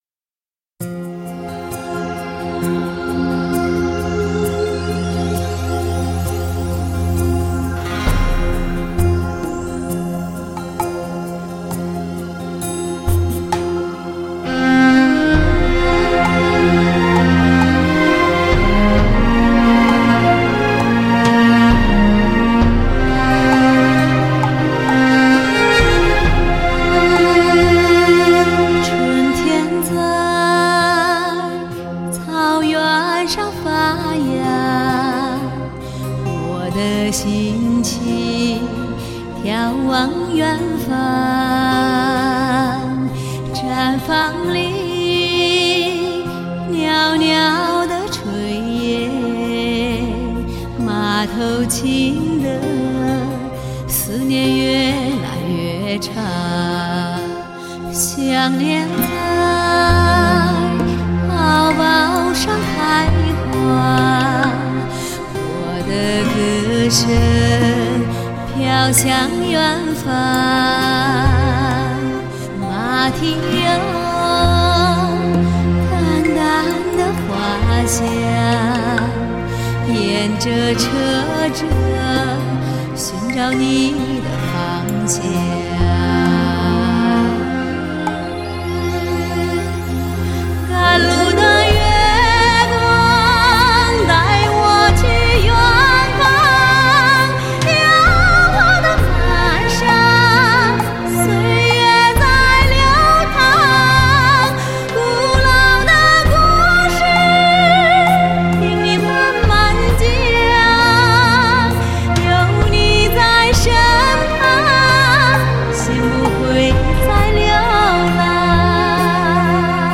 精确的录音，火热的激情，难忘绝色靓声，2011柔情给力之作，款款深情的歌声，
搜寻爱情的美丽记忆点，透过人声连结心与心最真诚的距离，为发烧友呈现最真诚的原音！